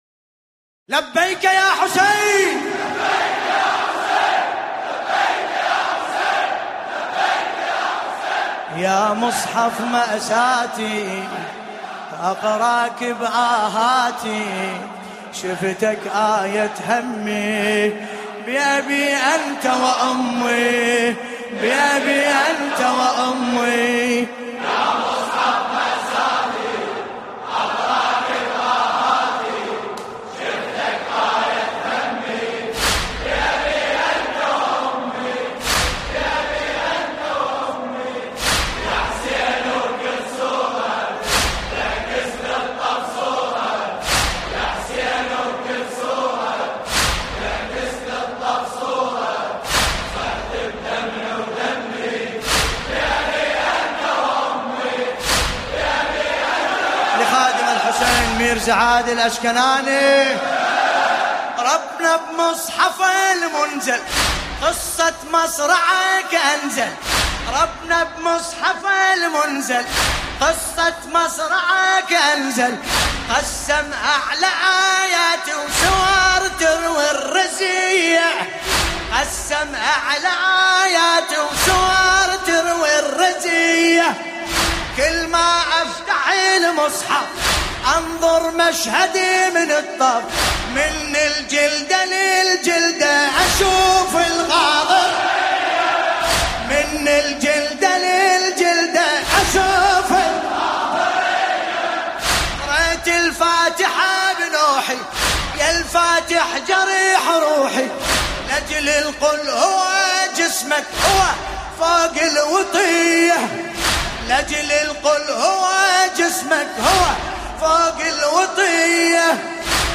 المناسبة : ليلة ١٨ محرم ١٤٤٠هـ